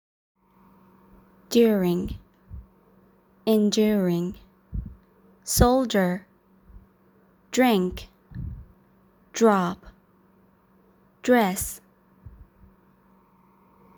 تلفظ حرف D
توی بعضی کلمات که حرف d دارن گاهی موقع تلفظ به‌جای «د»، صدای «ج» به گوش می‌رسه. در هر دو تلفظ بریتانیایی و امریکن این اتفاق می‌افته.